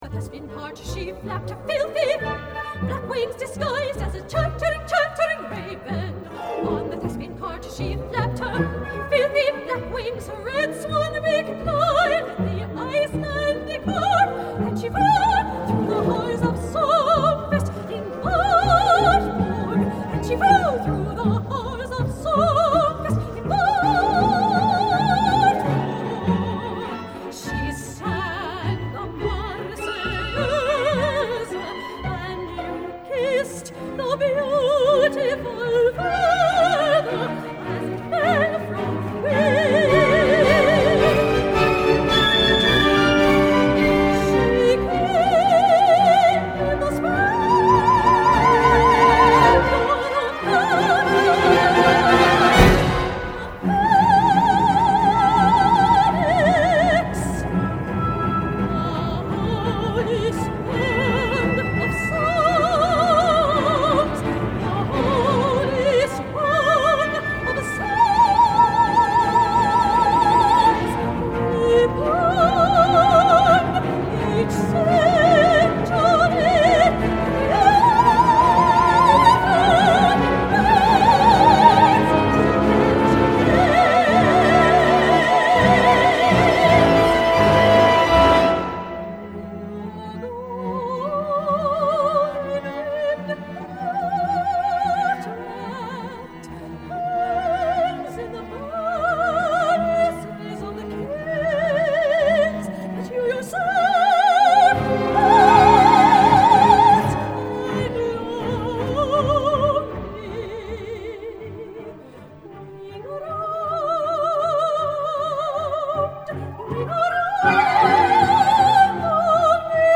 Concerto for Soprano and Orchestra